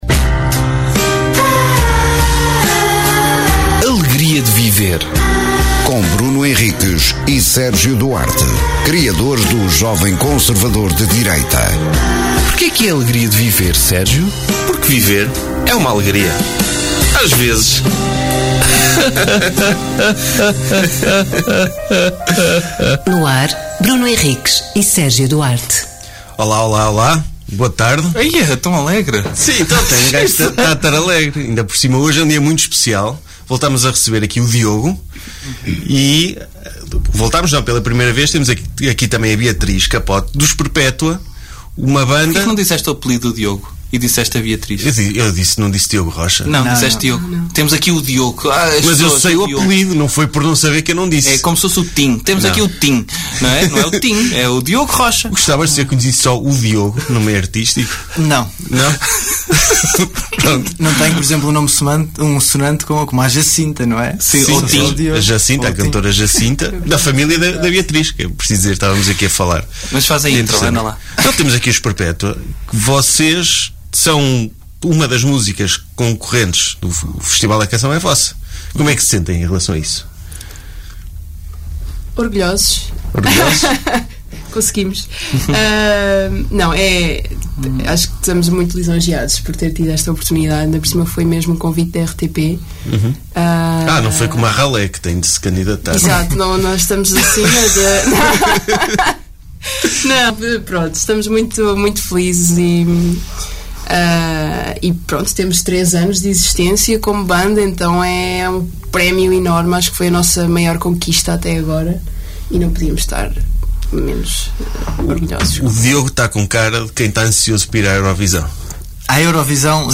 Sem preparação ou discussão prévia, abrem o microfone e partem em cada episódio para um tema desconhecido; que exploram com humor e sentido crítico… Uma hora transmitida em direto e sem filtros, que não poucas vezes terá convidados, estejam no estúdio ou fora dele…